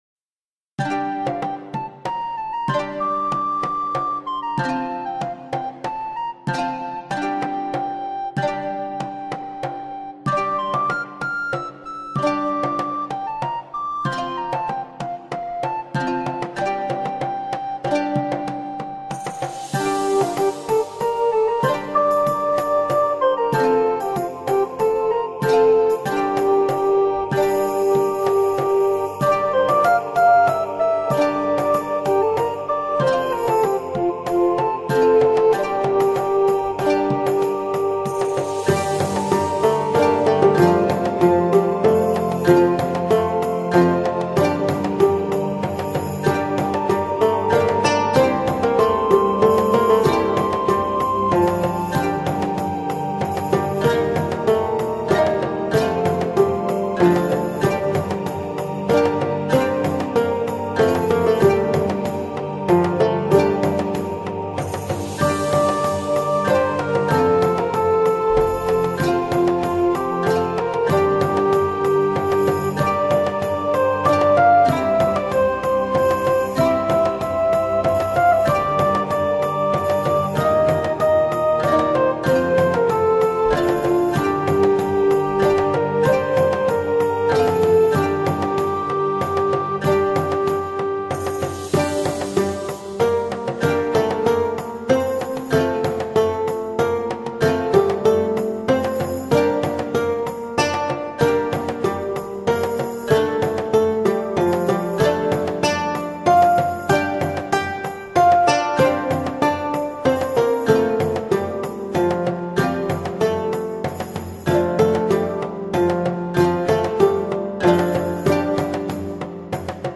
medieval_musique4.mp3